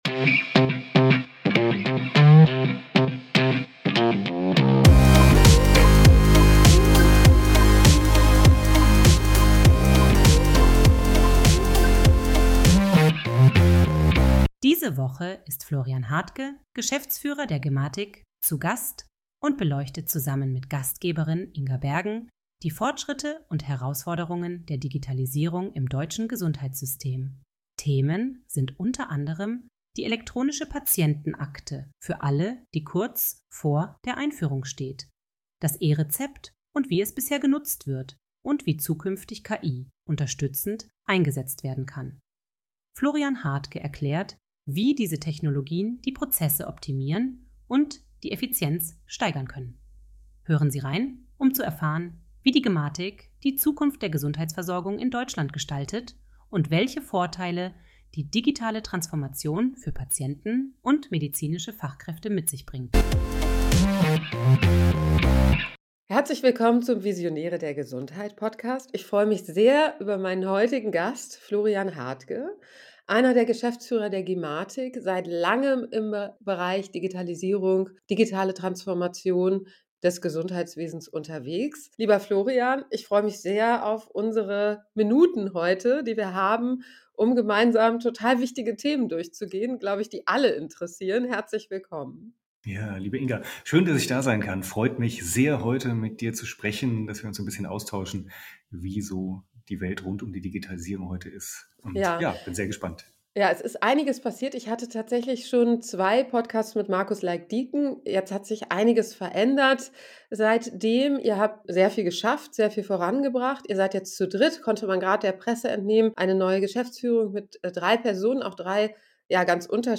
zu Gast.